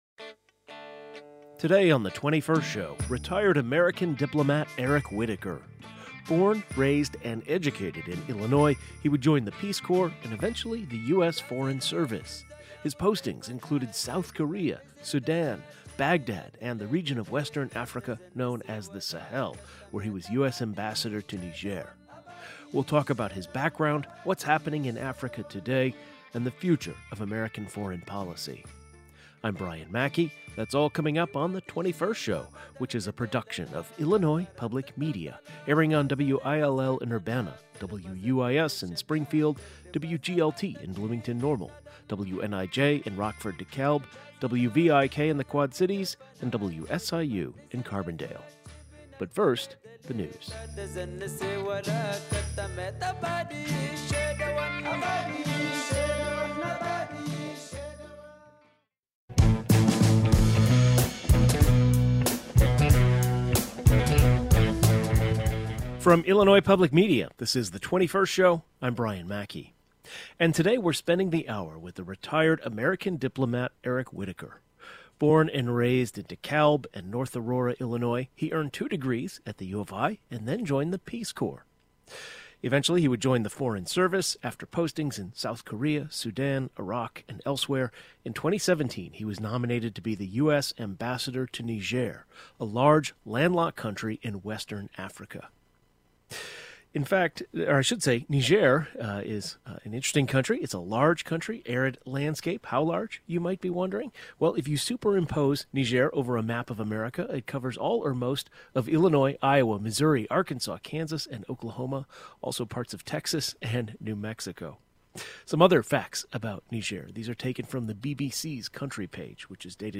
A conversation with retired U.S. Diplomat Eric P. Whitaker.